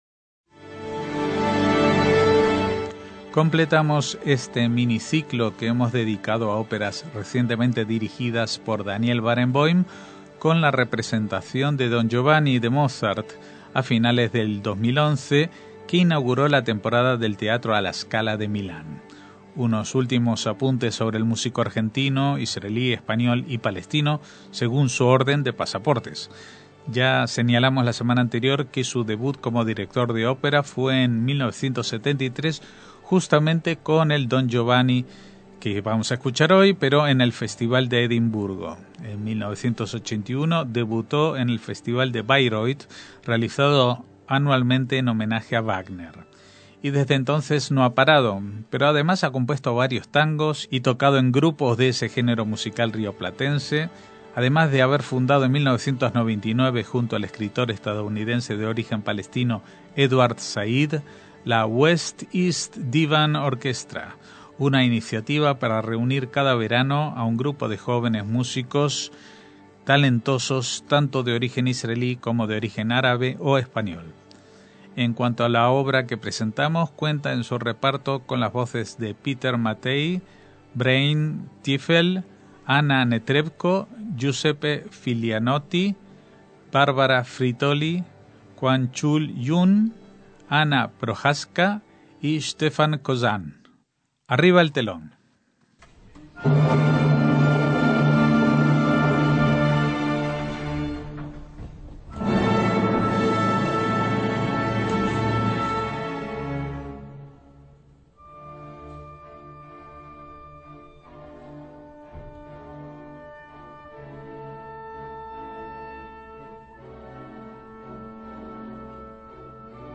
ÓPERA JUDAICA - Completamos este mini ciclo que hemos dedicado a óperas recientemente dirigidas por Daniel Barenboim con la representación del “Don Giovanni” de Mozart a finales de 2011 que inauguró la temporada del Teatro alla Scala de Milán.